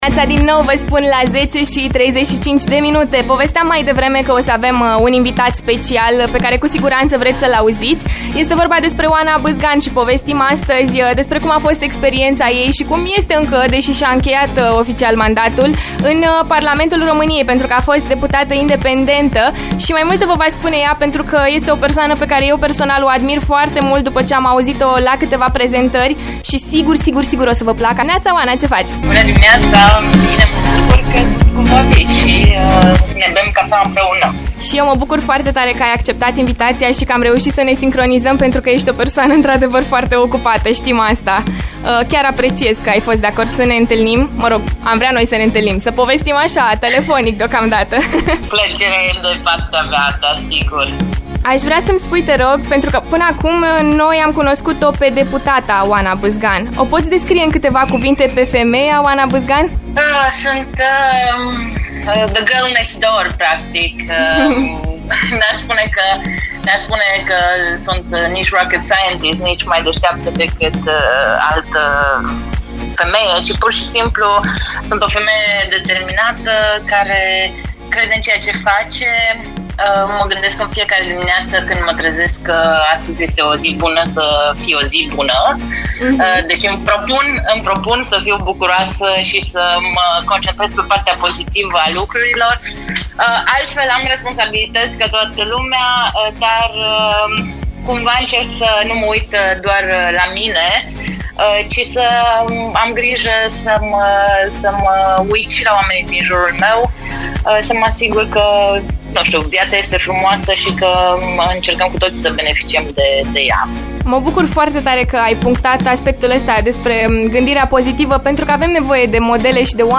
Oana Bîzgan, independenta care a scuturat o lume ”a bărbaților” – LIVE la After Morning